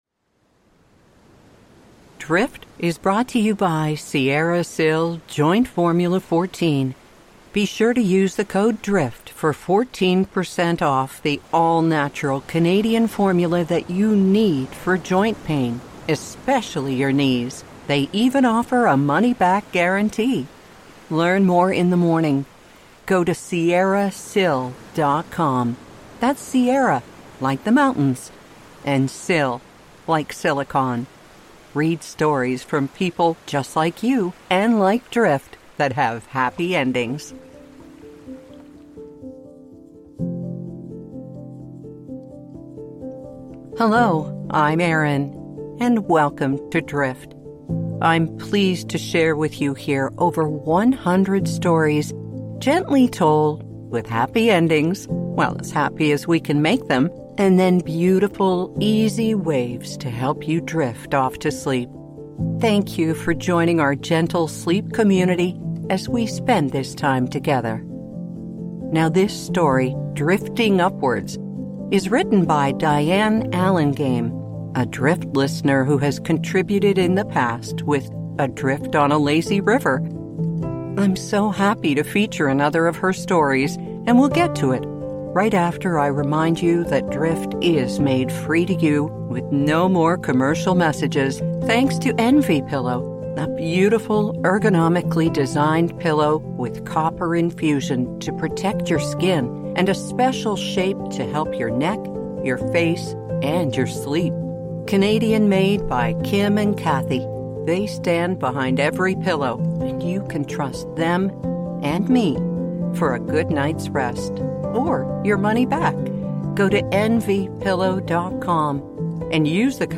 Sleep Stories